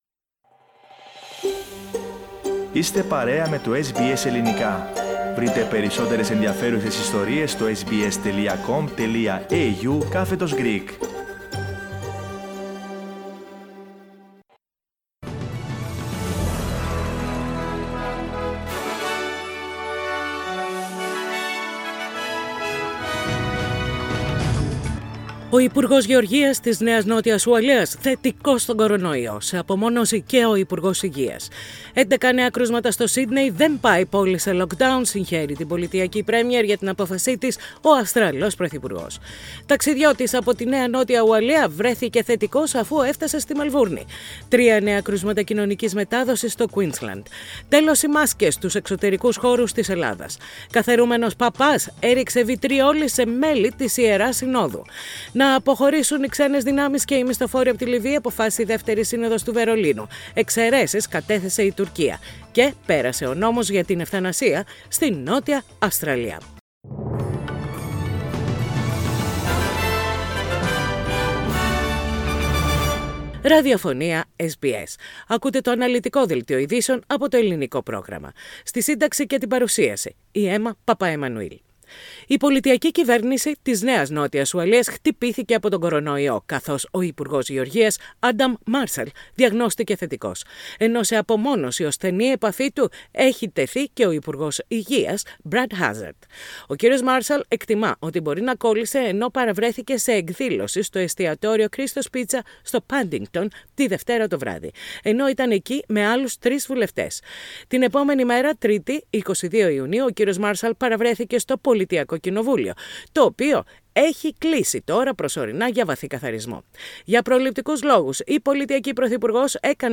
News in Greek - Thursday 24.6.21